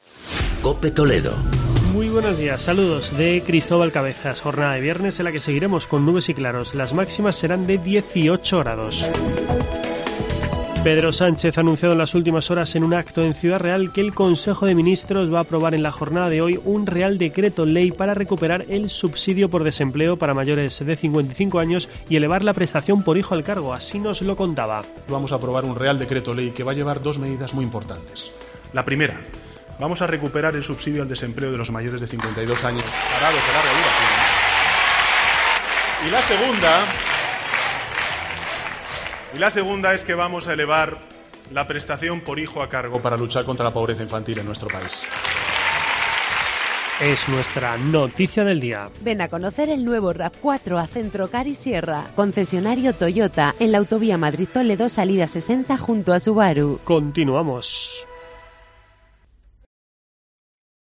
Así lo ha indicado en un acto público del PSOE celebrado en el Conservatorio de Música 'Marcos Redondo'
Boletín informativo de la Cadena COPE.